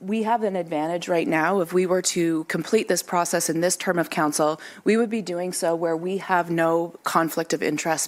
Councillor Clare Holloway Wadwani argued completing the work during the current term protects credibility, noting council members would not be influencing boundaries affecting their own re-election.